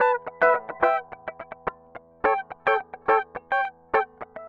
Retro Funkish Guitar 01d.wav